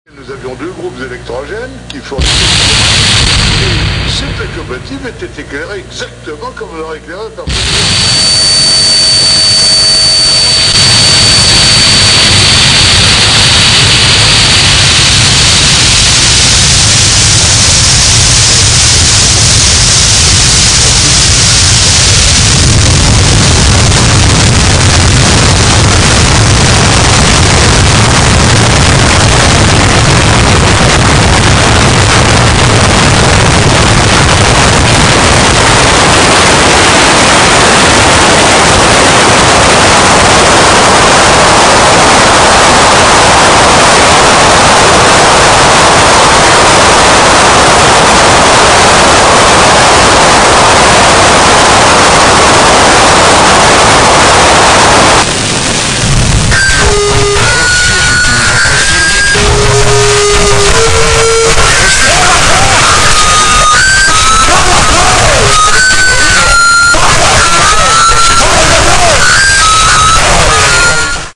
harsh noise storm